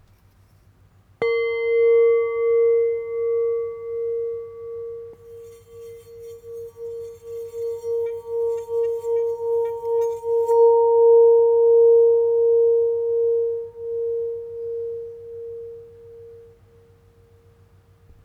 B Note 5″ Singing Bowl